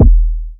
KICK.44.NEPT.wav